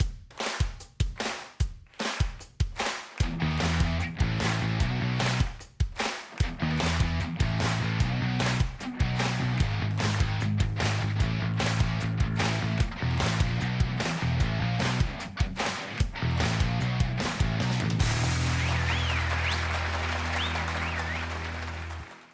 A red streamer theme
Ripped from the game
clipped to 30 seconds and applied fade-out